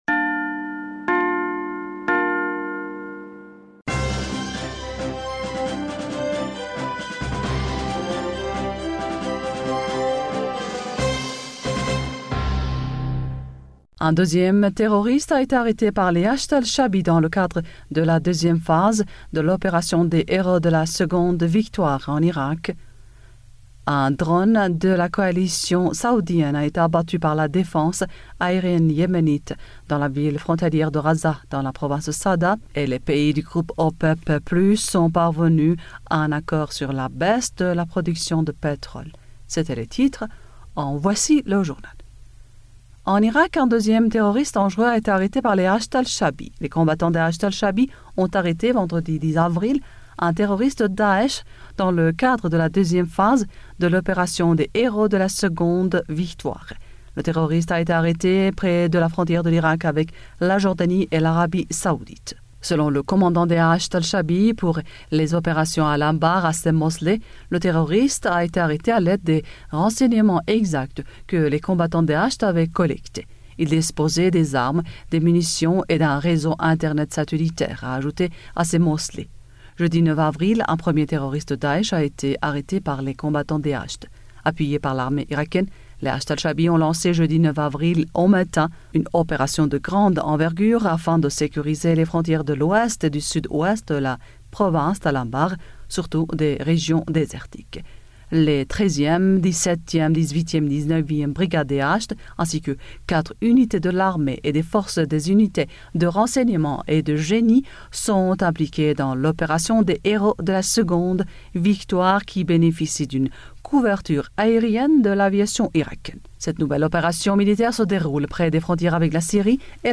Bulletin d'information du 11 avril 2020